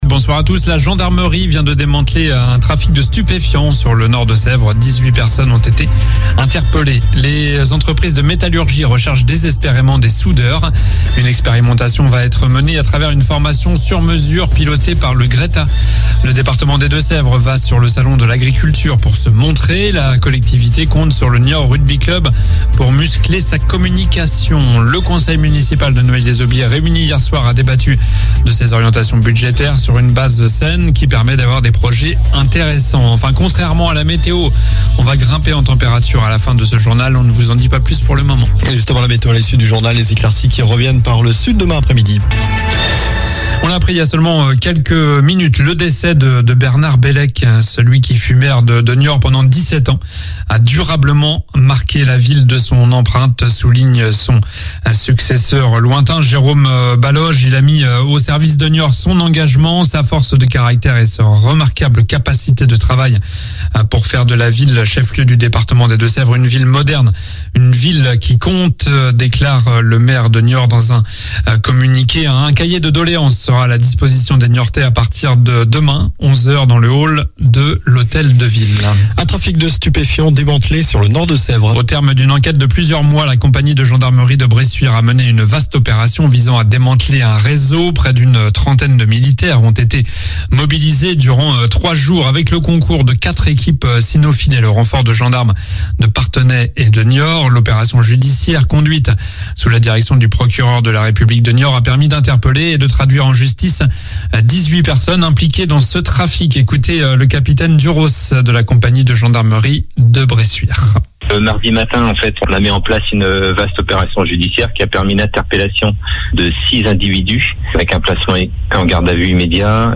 Journal du jeudi 23 février (soir)